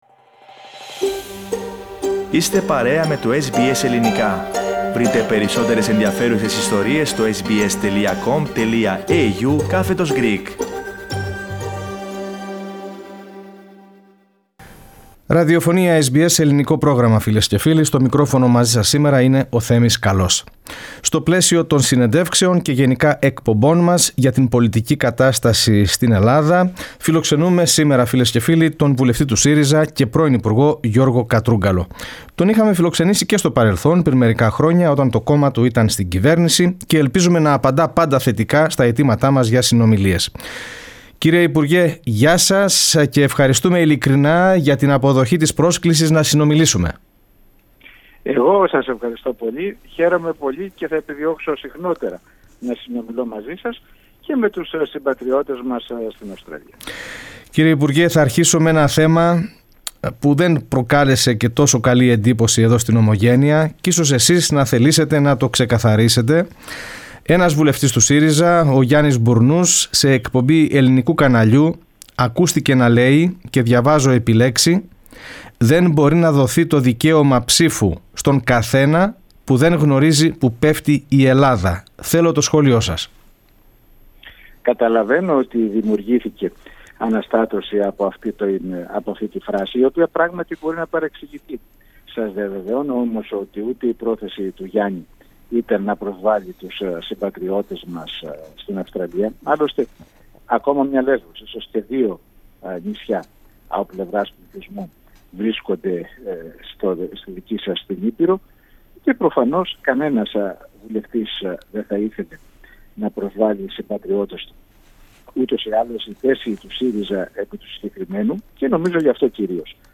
Σε συνέντεξη που παραχώρησε στο ελληνικό πρόγραμμα της SBS, ο συνάδελφός του βουλευτής, Γιώργος Κατρούγκαλος κλήθηκε να σχολιάσει την ερμηνεία του σχολίου και τις αντιδράσεις.